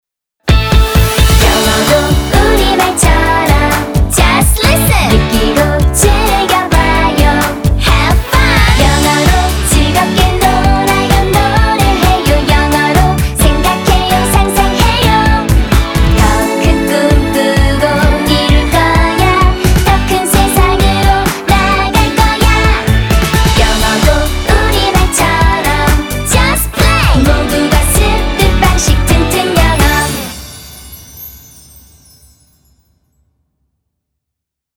즐겁고 신나는 분위의 곡으로 만들어졌습니다.
반복되는 리듬으로 한 번 들으면 계속 생각나는 곡이랍니다.